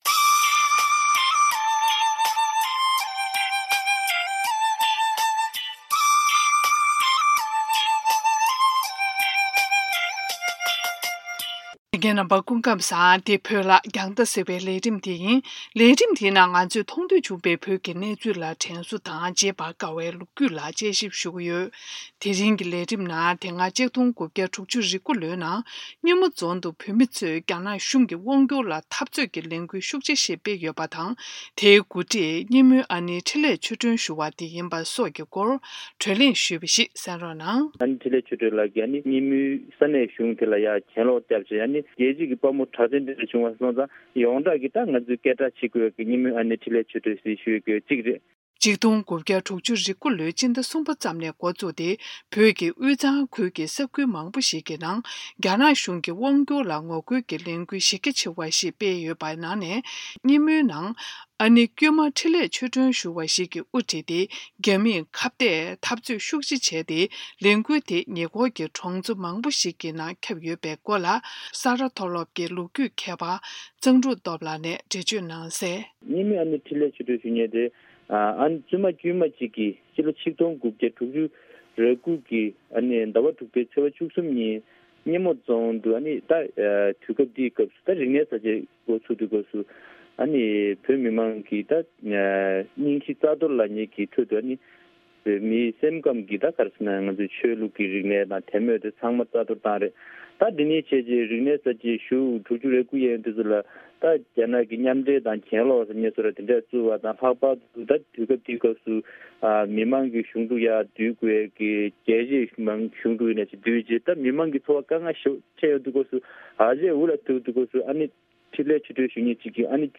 དེ་རིང་གི་མཐོང་ཐོས་བྱུང་བའི་བོད་ཀྱི་གནས་ཚུལ་དྲན་གསོའི་ལས་རིམ་ནང་དེ་སྔ་༡༩༦༩ལོའི་ནང་སྙེ་མོ་རྫོང་དུ་ཨ་ནེ་འཕྲིན་ལས་ཆོས་སྒྲོན་གྱིས་དབུ་ཁྲིད་དེ་རྒྱ་ནག་གཞུང་ལ་ངོ་རྒོལ་གྱི་གྱེན་ལངས་གཞི་རྒྱ་ཆེ་བ་ཞིག་བྱས་ཡོད་པའི་སྐོར་བགྲོ་གླེང་ཞུས་པ་ཞིག་གསན་རོགས་གནང་།